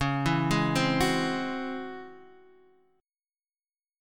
DbmM11 Chord